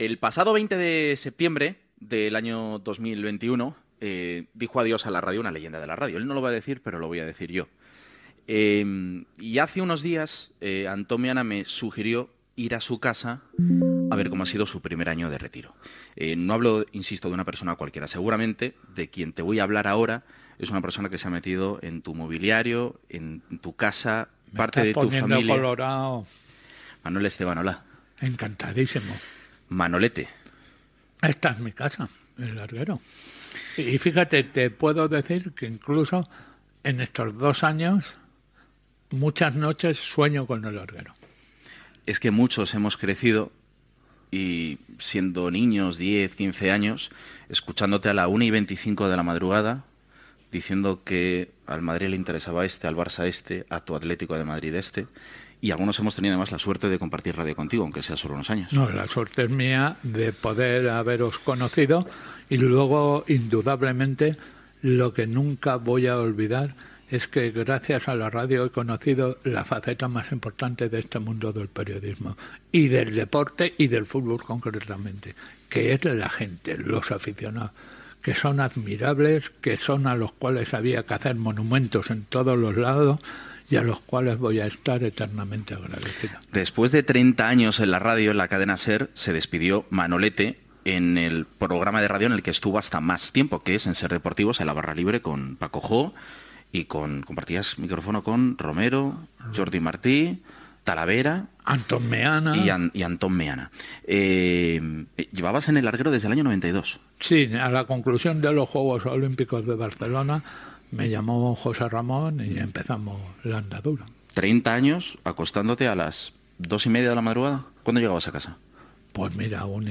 Fragment d'una entrevista